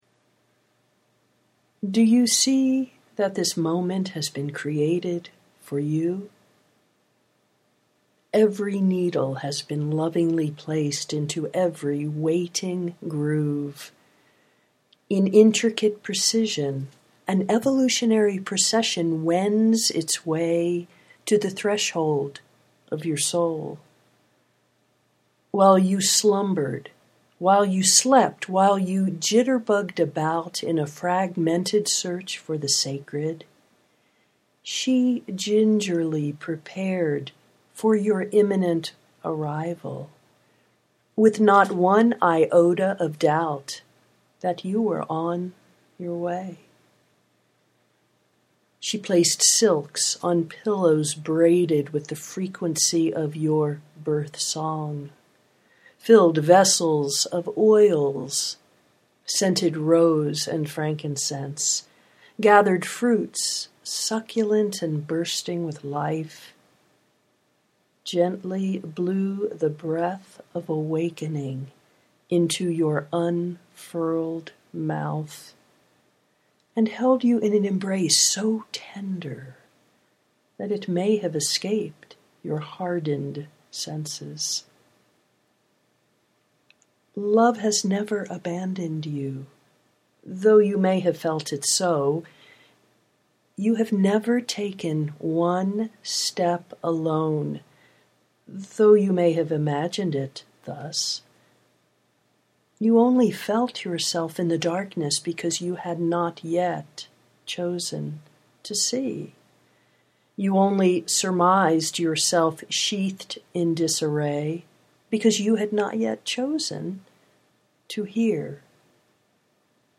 love has never abandoned you (audio poetry 3:46)